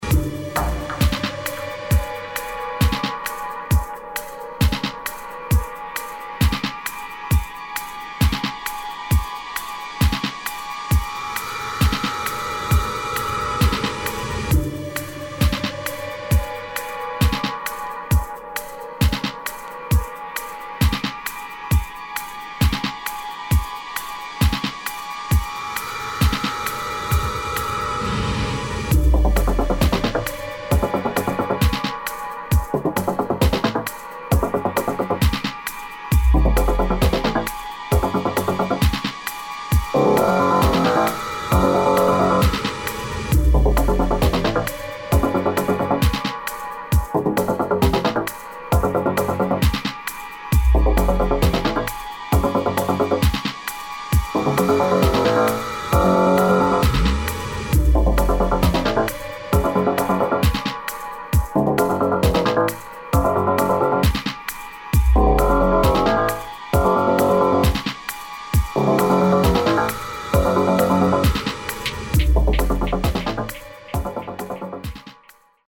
[ BASS / TECHNO ]